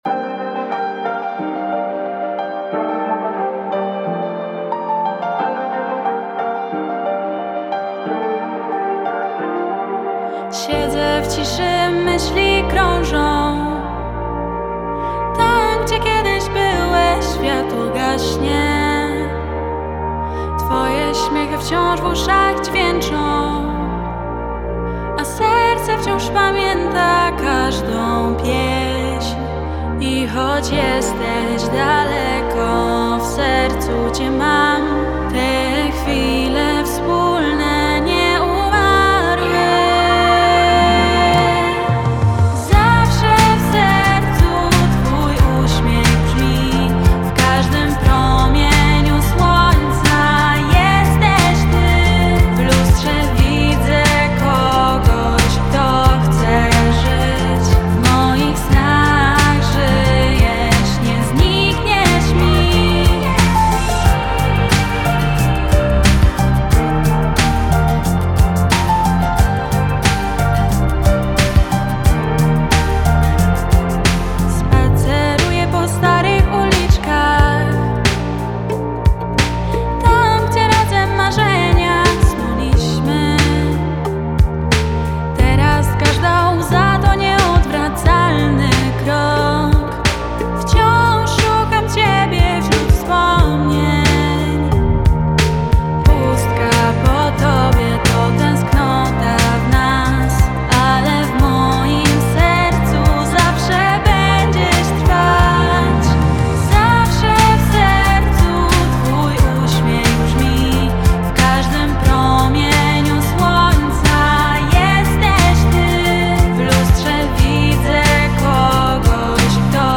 ballada okolicznościowa